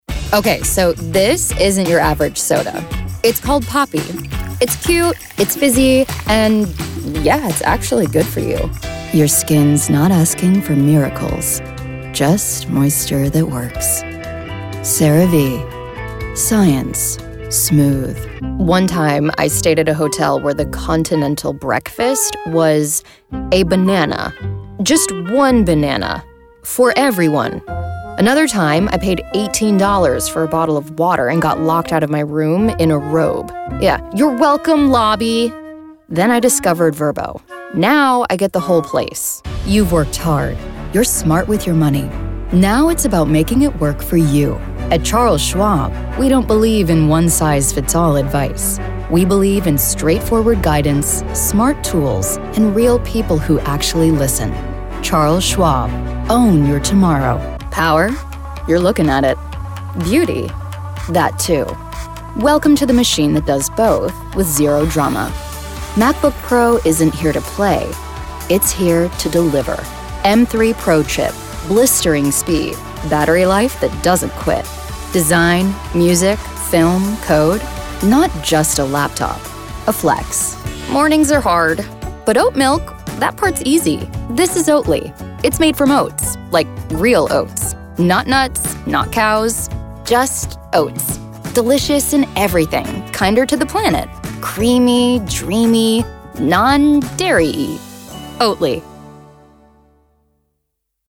Relatable, Authentic Vibe :)
English - USA and Canada
Young Adult
Commercial